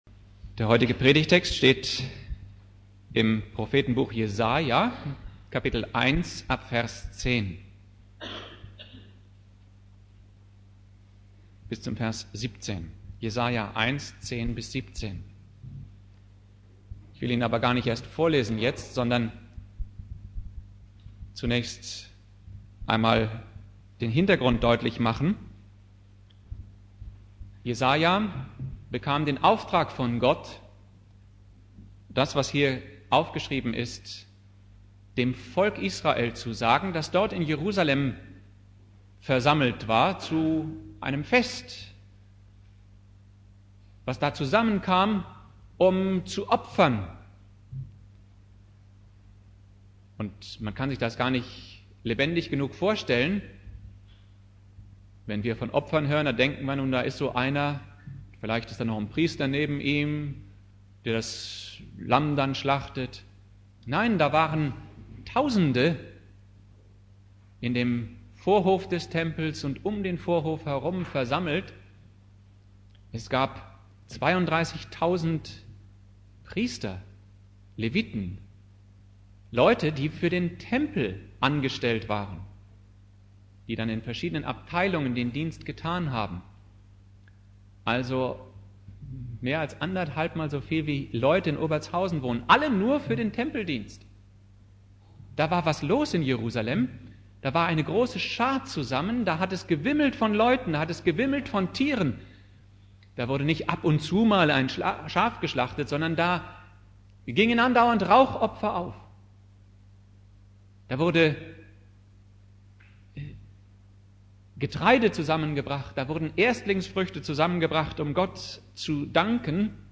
Predigt
Buß- und Bettag Prediger